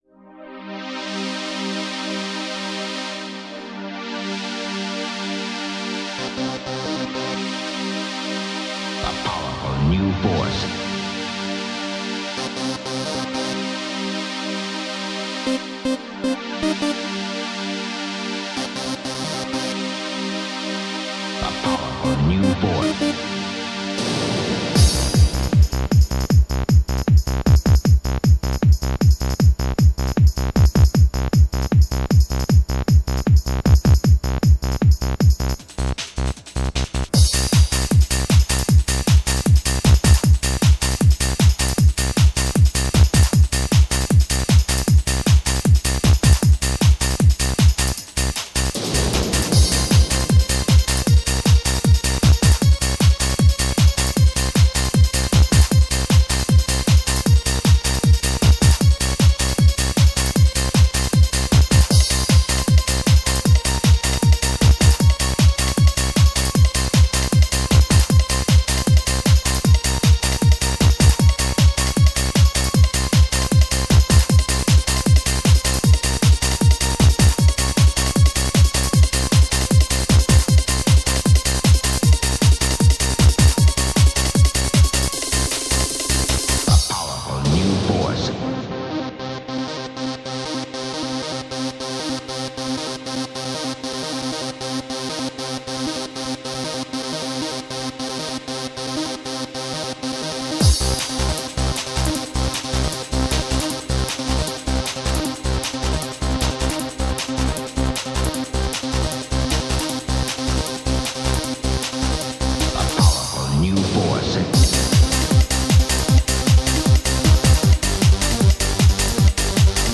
Жанр: HardTrance